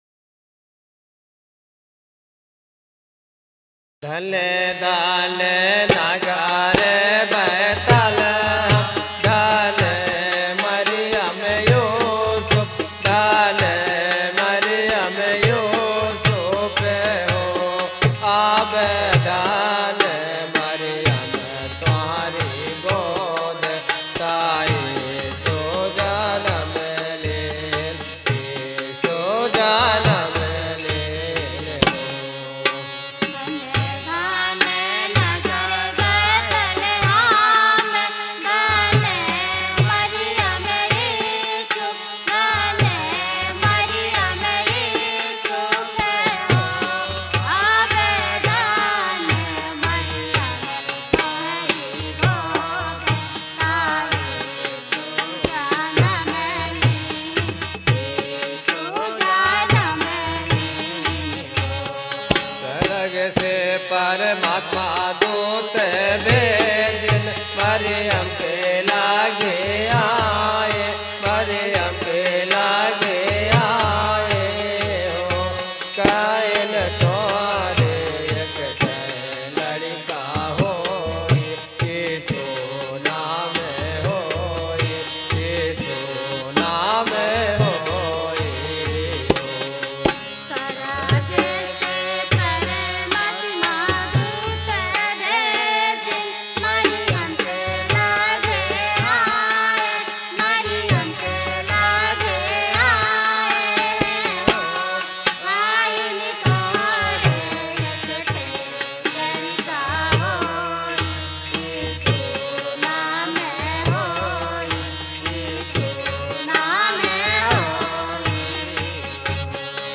Jesus birth, death, resurrection, his ascending to heaven and miracles did by Jesus, Stories which were told by Jesus himself, are made as 16 stories in Bagheli and also recorded in song form.